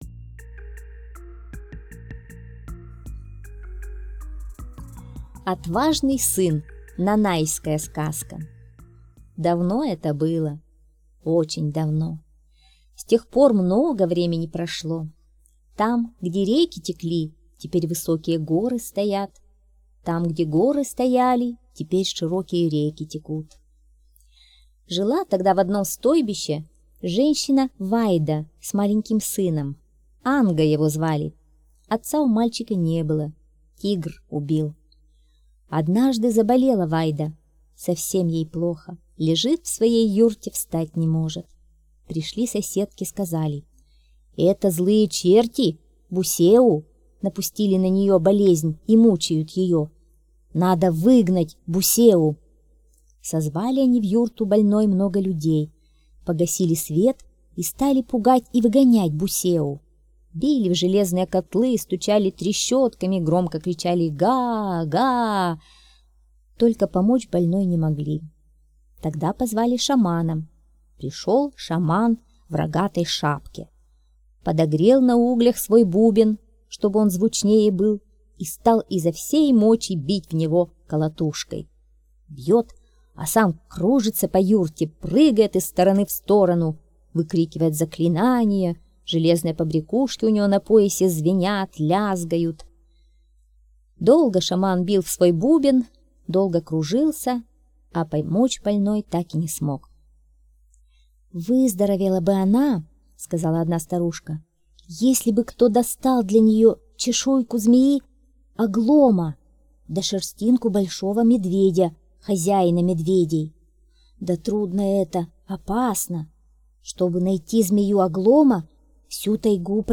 Отважный сын - нанайская аудиосказка - слушать онлайн